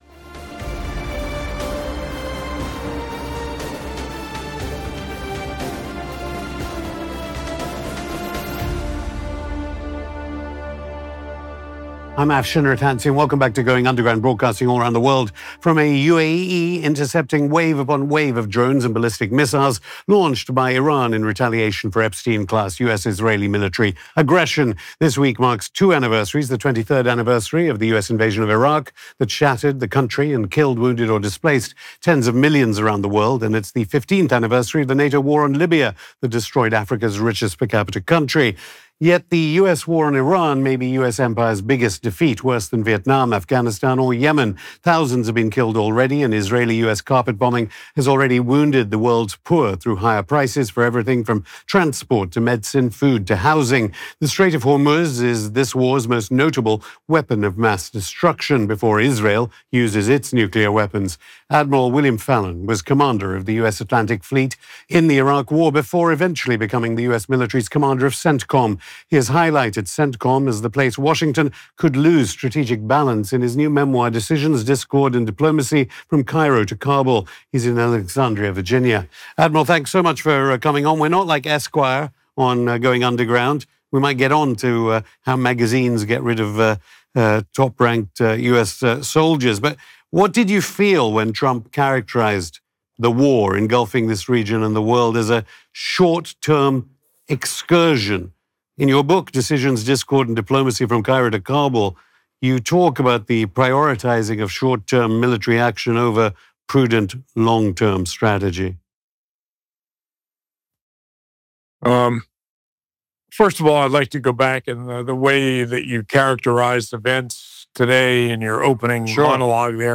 On this episode of Going Underground, we speak to the Former Commander of US Central Command (CENTCOM), William Fallon. In a heated interview, Afshin Rattansi challenges him on the justifications for the US-Israel War on Iran, the Minab Girls School strike which killed 175+, the parallels between this war of aggression and the 2003 Iraq War, the regional destabilisation unleashed on the Gulf States by the US-Israeli war, and much more.